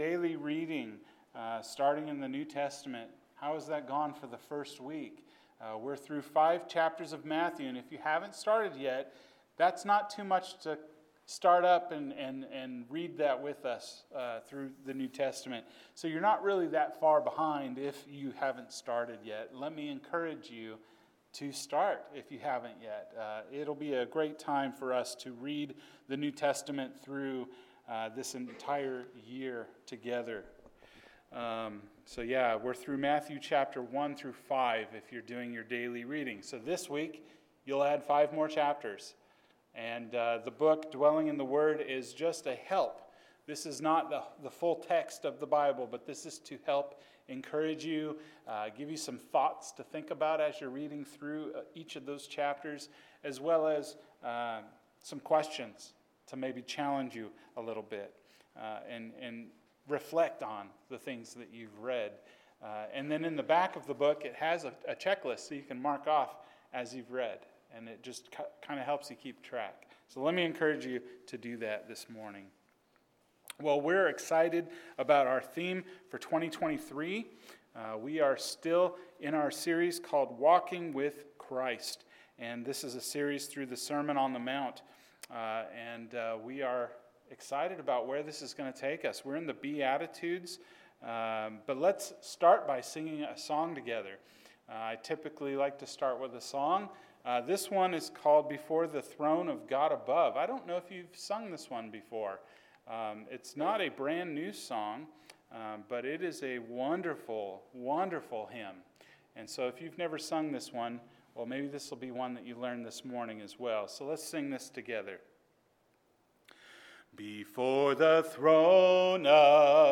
The Beatitudes – Those Who Mourn – Sermon